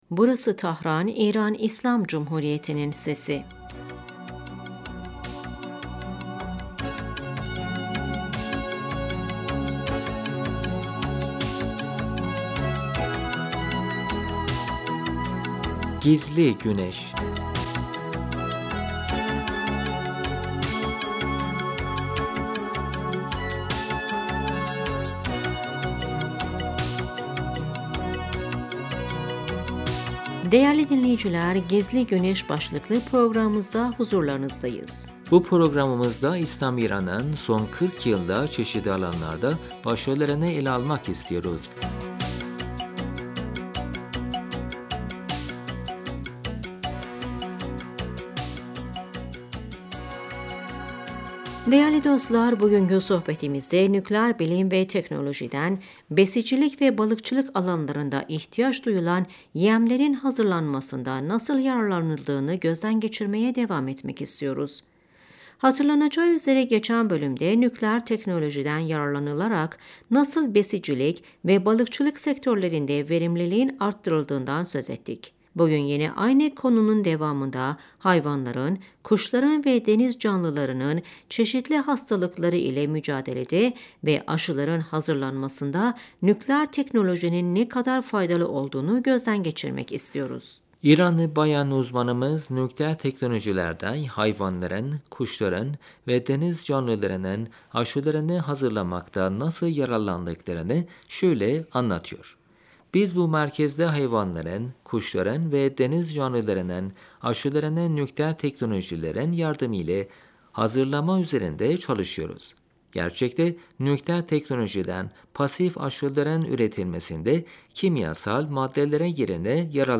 İranlı bayan uzmanımız nükleer teknolojilerden hayvanların, kuşların ve deniz canlılarının aşılarını hazırlamakta nasıl yararlandıklarını şöyle anlatıyor: Biz bu merkezde hayvanların, kuşların ve deniz canlılarının aşılarını nükleer teknolojilerin yardımıyla hazırlama üzerinde çalışıyoruz.